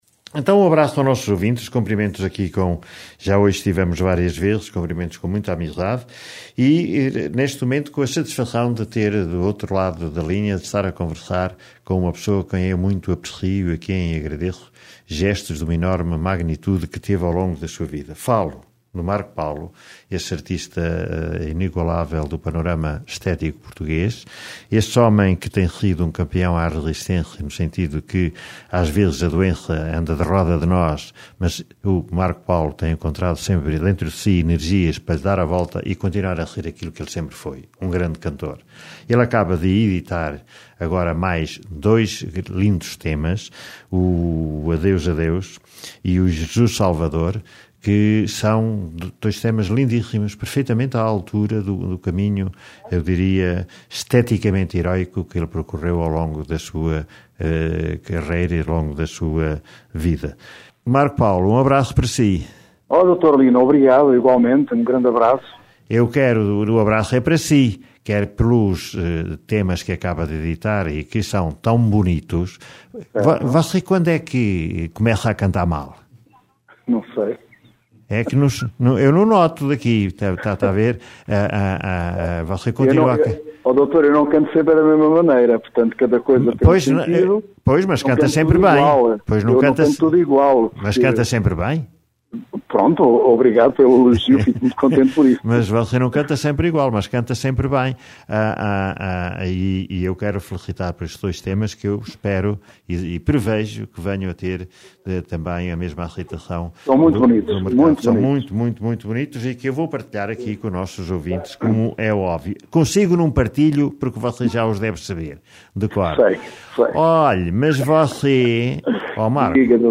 No dia do seu aniversário, Marco Paulo conversou com a Rádio Regional do Centro - Rádio Regional Centro